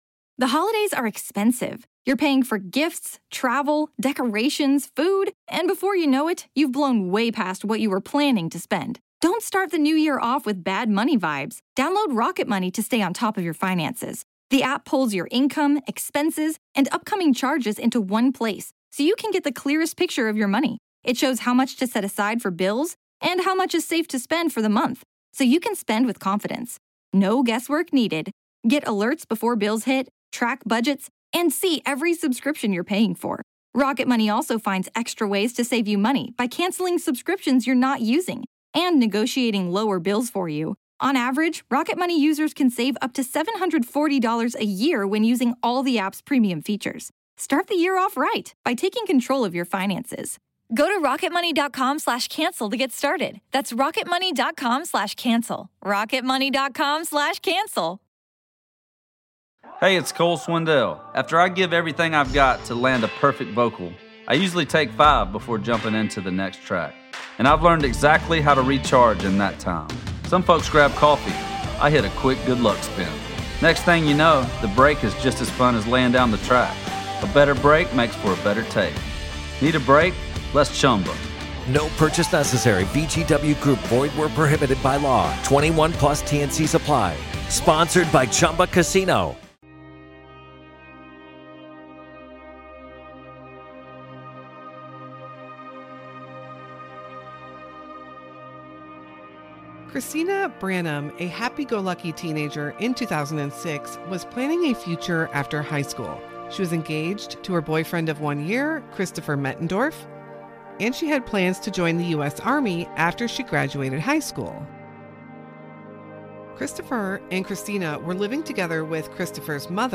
Audio may differ slightly from our regular program.